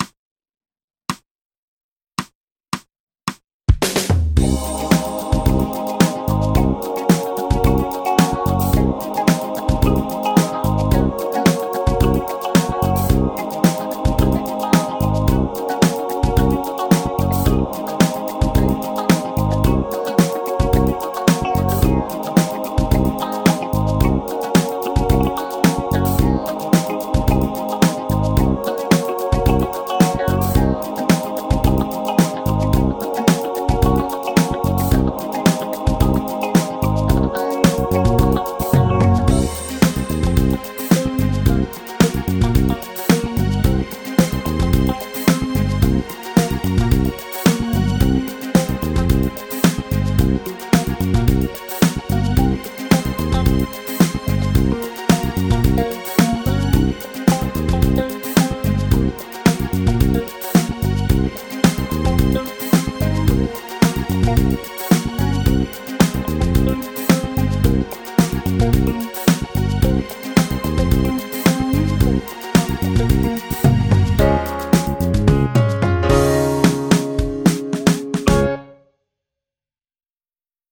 ロクリアン・スケール ギタースケールハンドブック -島村楽器